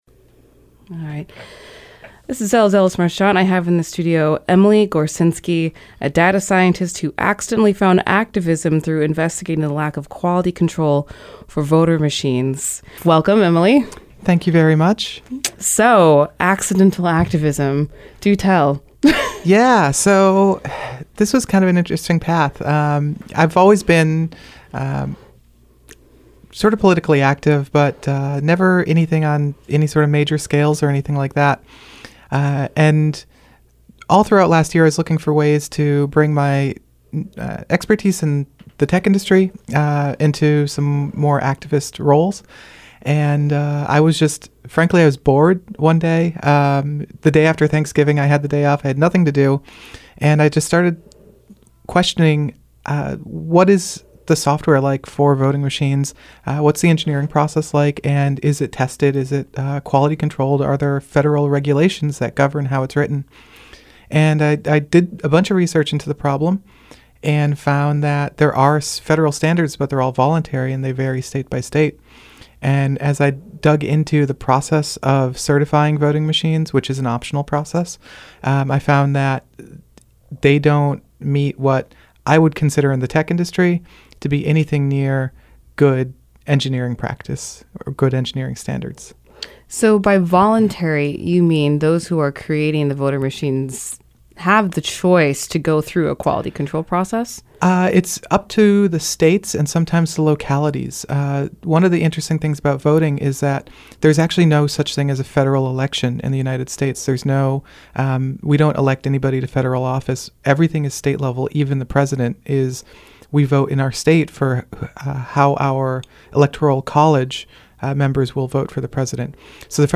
KBOO Program:: Evening News Air date